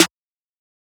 MZ Snare [Metro Bape Lo].wav